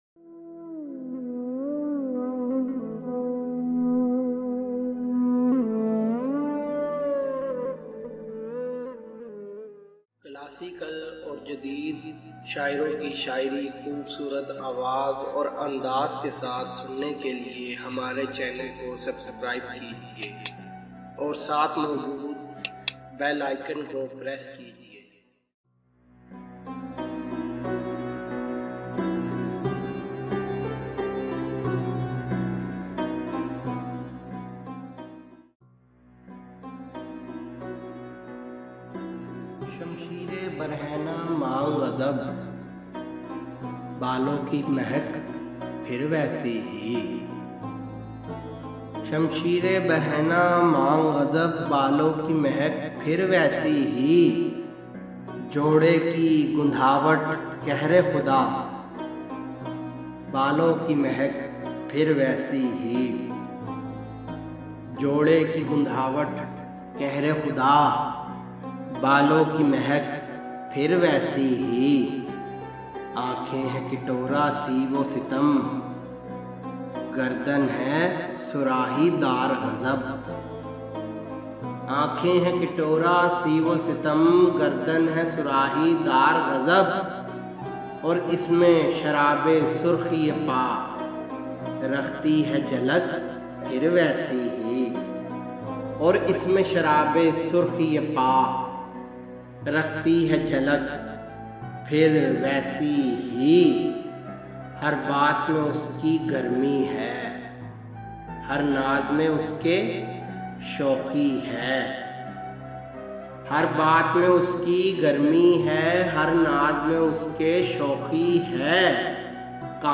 Bahadur shah zafar, Audiobooks